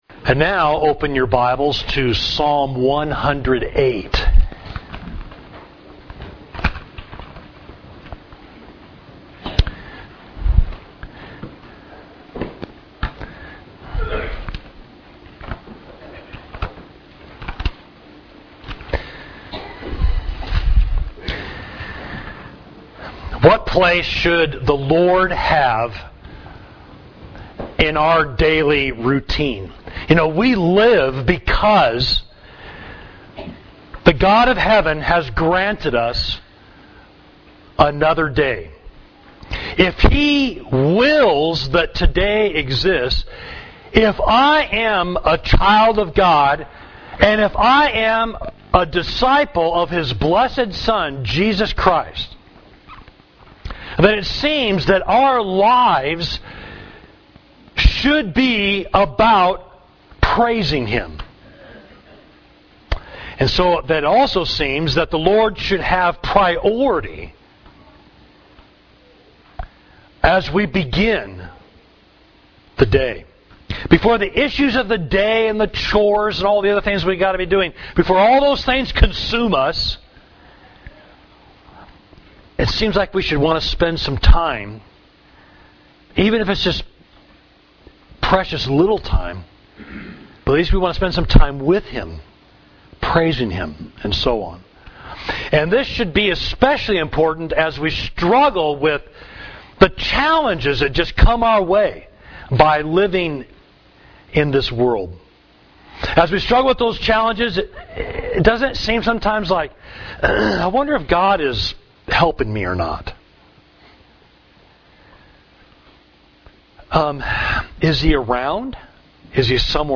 Sermon: The Source of Your Final Help, Psalm 108